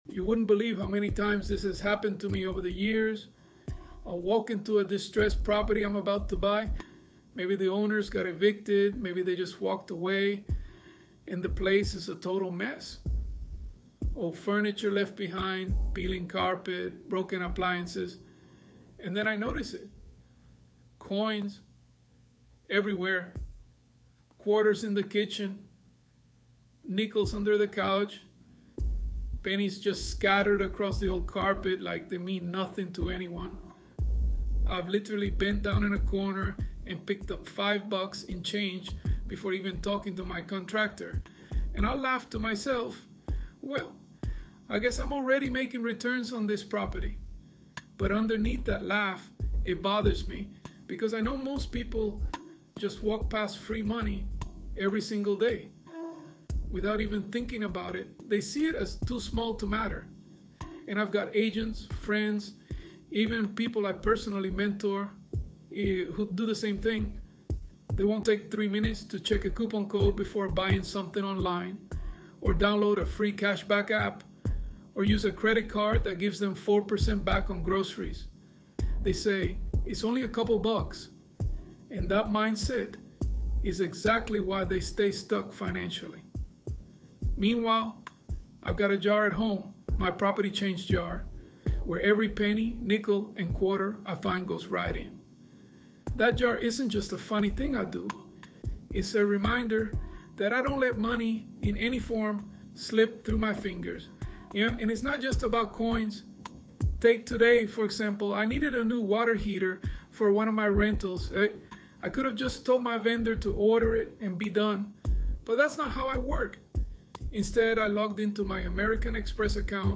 ElevenLabs_Untitled_Project-10.mp3